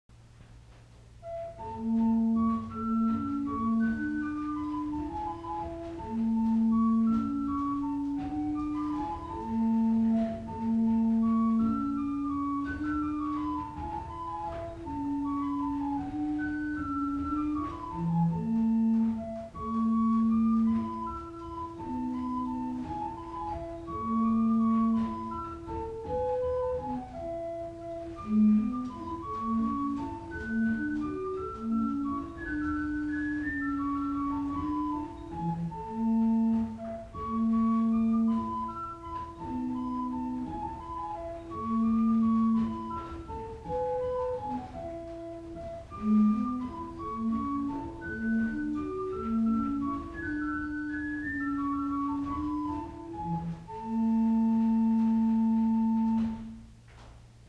1802 Tannenberg Organ
Hebron Lutheran Church - Madison, VA
The Gedackt is stopped wood and the Flaute is open.
Listen to Country Dance: Miss Smith's Waltz by Alexander Reinagle played on the Flaute 4' by clicking